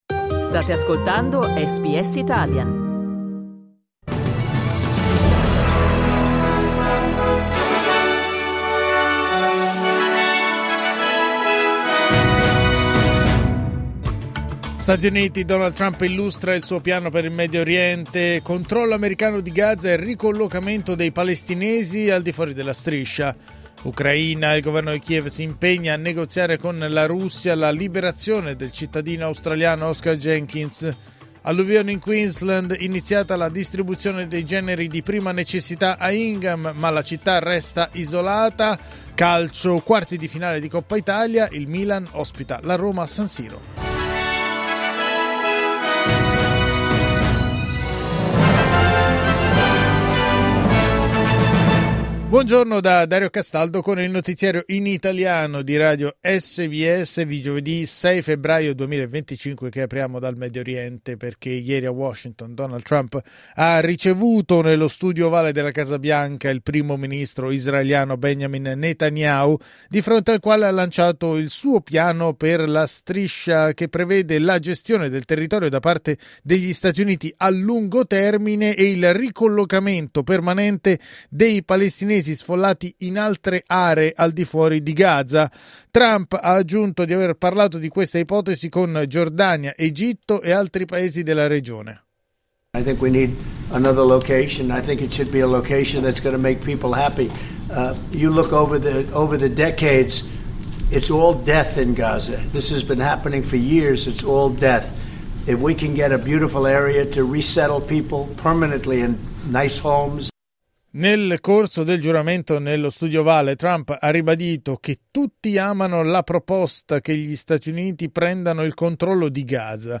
Giornale radio giovedì 6 febbraio 2025
Il notiziario di SBS in italiano.